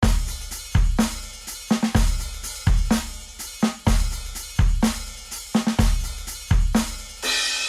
Tag: 125 bpm Chill Out Loops Drum Loops 1.29 MB wav Key : Unknown